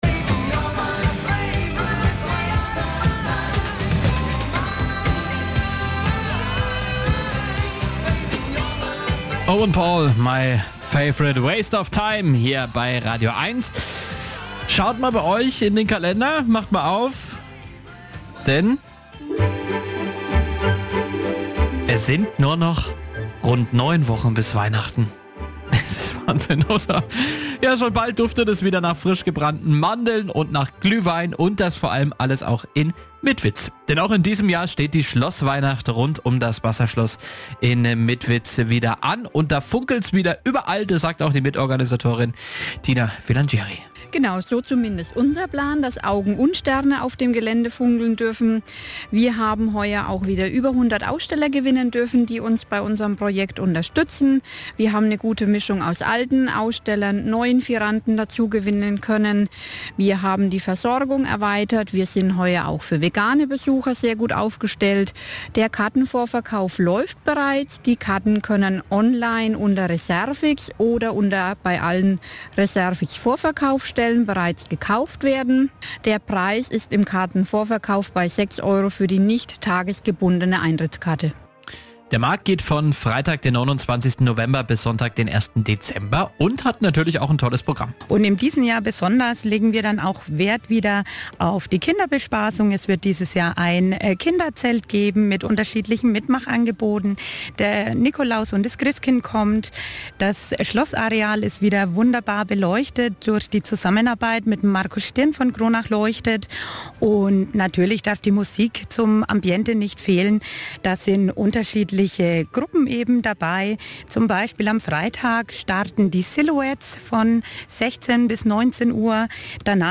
Die Mitwitzer Schlossweihnacht in den Medien Sie finden die Mitwitzer Schlossweihnacht in zahlreichen Medien. Radiobeitrag „ Radio Eins